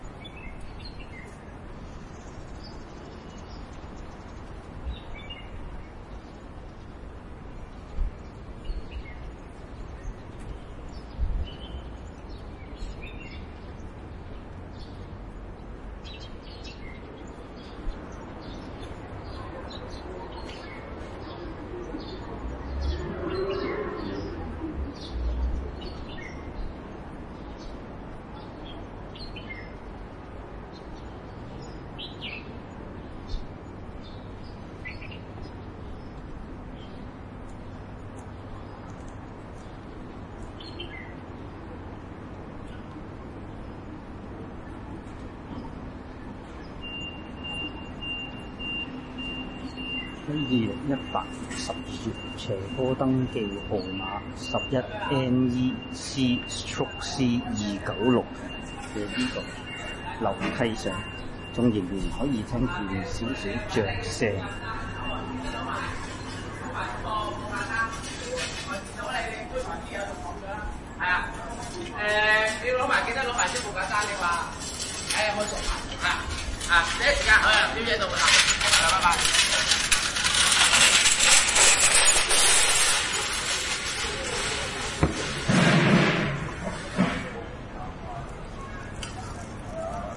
Biblioteca UPF " 大声的脚步声和声音
描述：两个人走下楼梯，周围的人发出声音
Tag: 校园UPF UPF-CS12 脚步声 声音